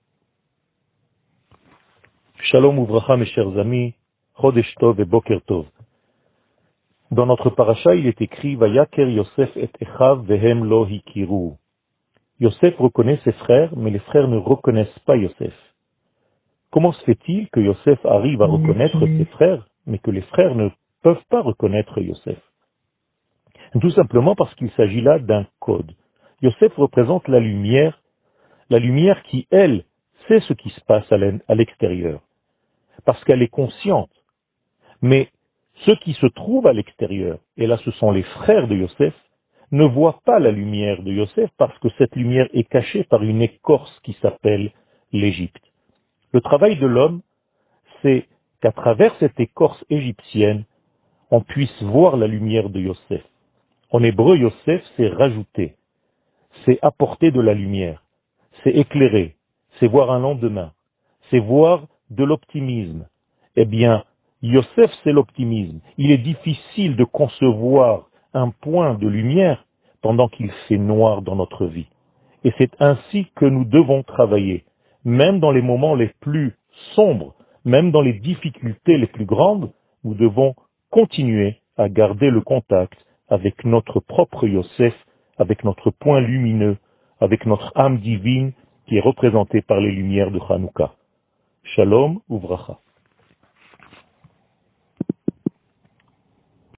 שיעור מ 17 דצמבר 2020
שיעורים קצרים